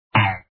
1_Twang.mp3